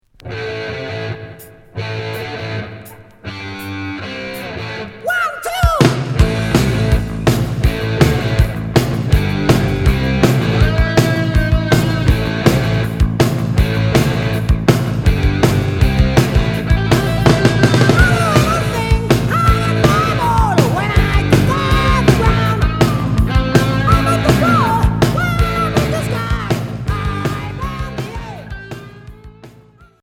Hard heavy